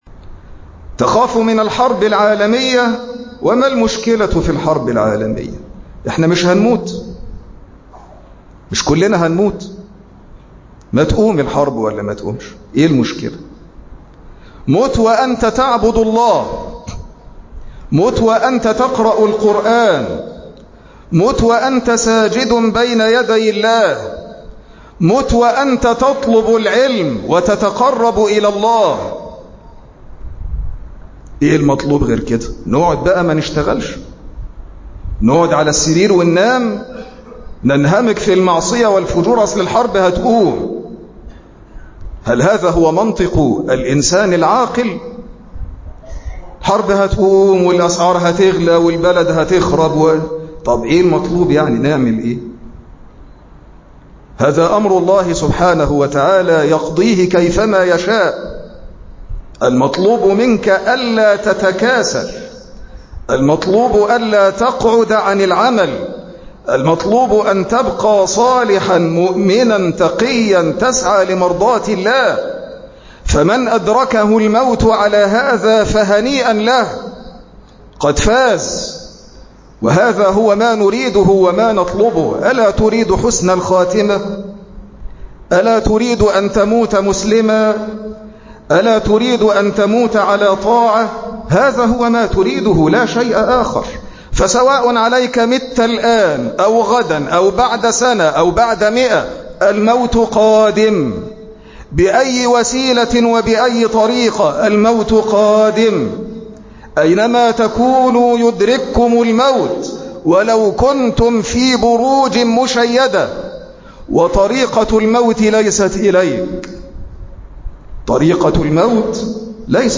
مقطع من خطبة الجمعة